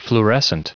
Prononciation du mot fluorescent en anglais (fichier audio)
Prononciation du mot : fluorescent